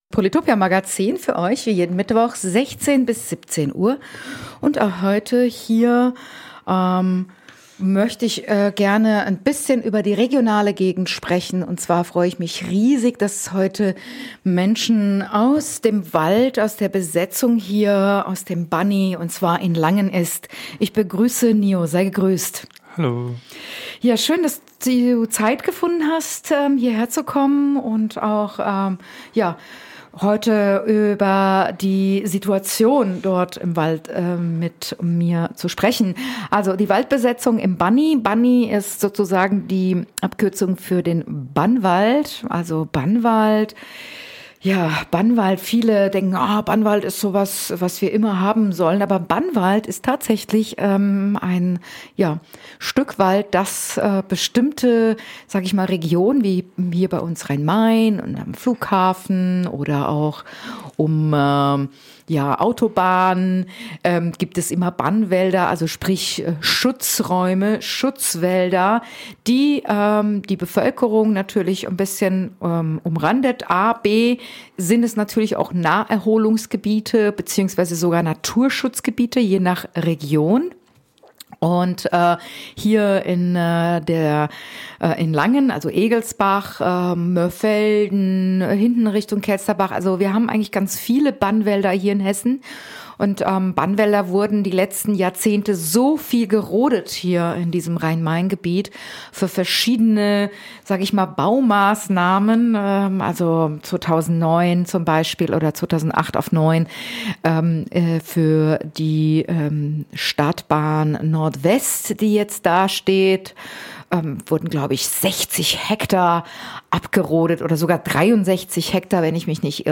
Wir sprechen mit den Aktivisten der Besetzung aus dem Langener Wald